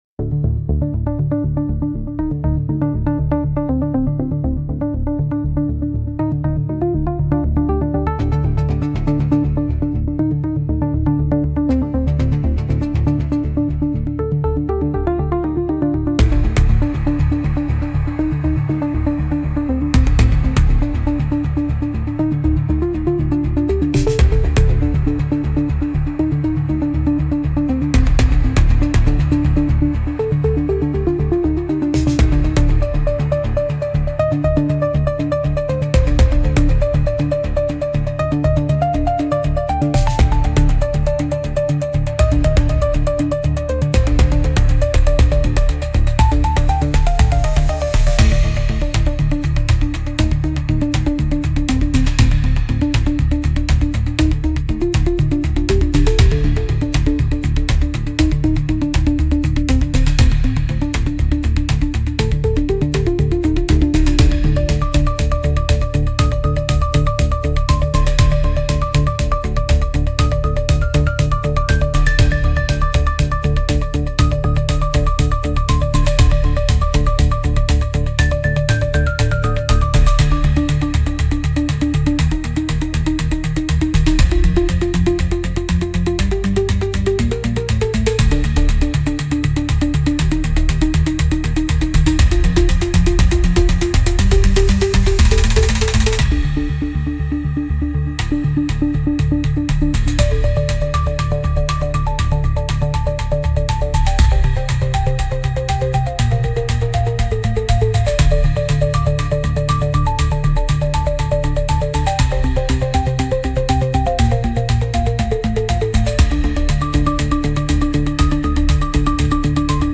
Instrumental - Bass Ritual - 4. 00 Mins